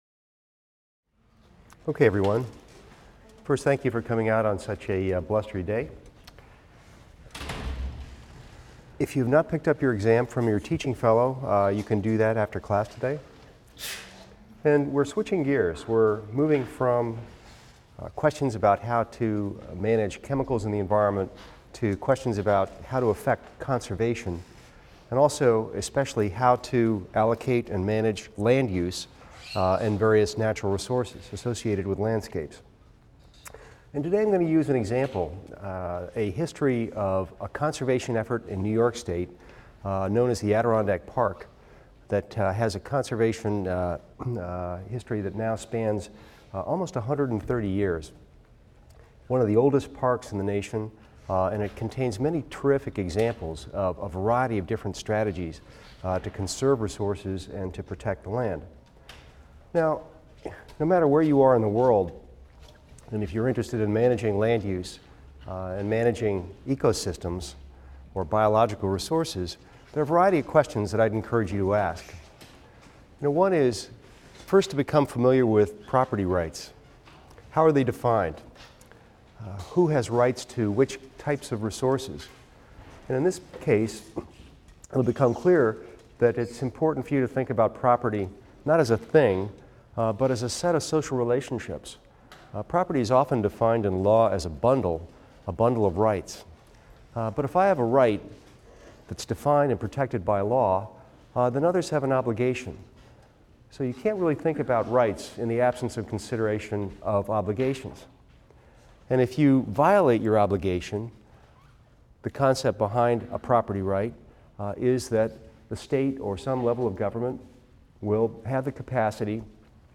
EVST 255 - Lecture 17 - Land Use and Conservation Law: The Adirondack History | Open Yale Courses